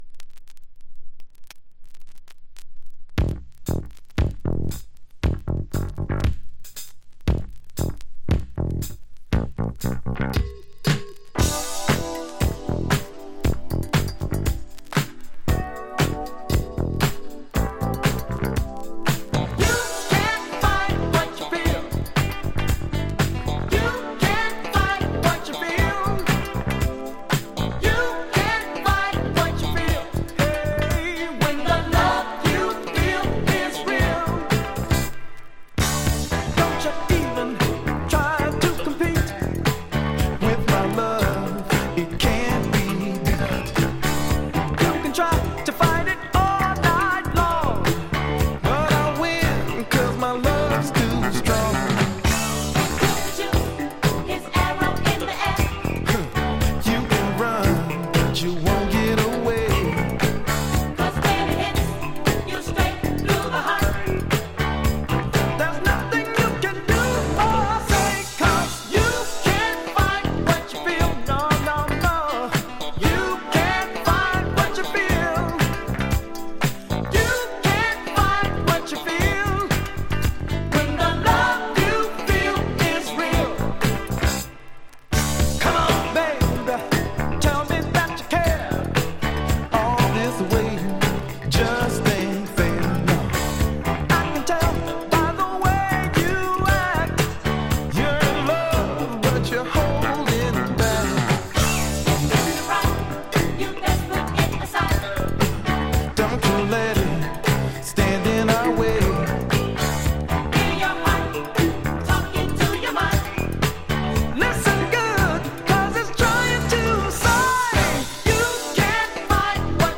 Disco Funk at its bestな超ご機嫌な仕上がりの名盤！
過去に販売した盤（日本盤のEX）からの録音です。